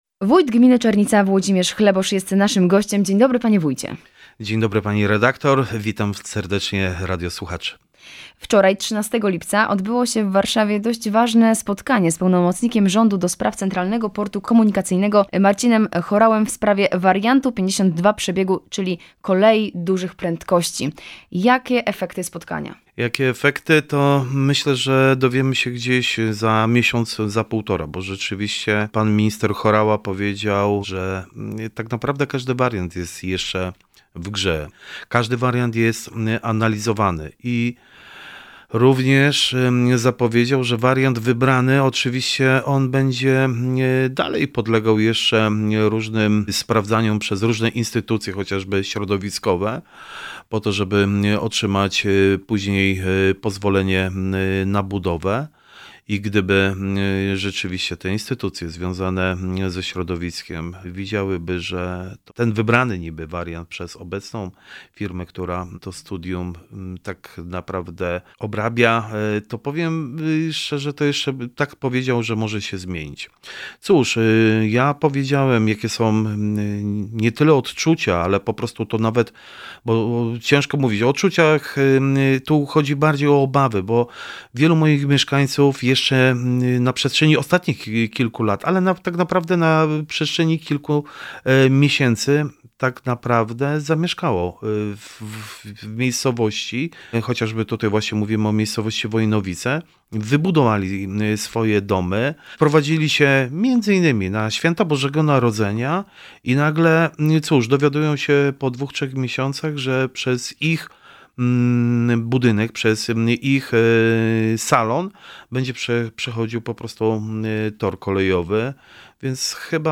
Spotkanie z pełnomocnikiem rządu ds. CPK Marcinem Horałą dot. Kolei Dużych Prędkości, współfinansowanie dodatkowych połączeń kolejowych czy budowa łącznika do Wschodniej Obwodnicy Wrocławia w Dobrzykowicach – rozmawiamy z Włodzimierzem Chleboszem – wójtem gminy Czernica.
Rozmowa-z-Wlodzimierzem-Chleboszem-wojtem-gminy-Czernica.mp3